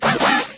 sneasler_ambient.ogg